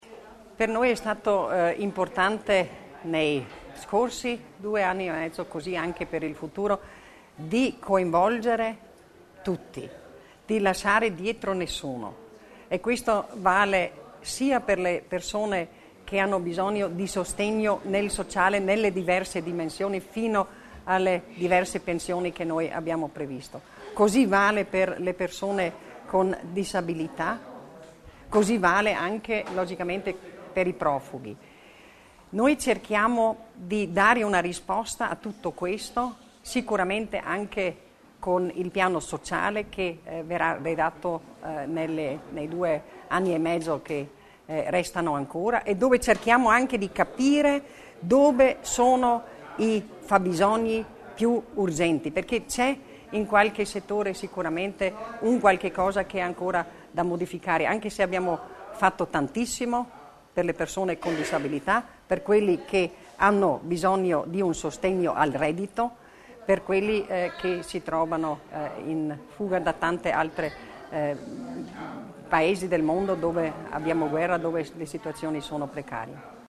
La seconda parte della conferenza stampa dell’assessora Stocker è stata dedicata al sostegno delle persone in difficoltà.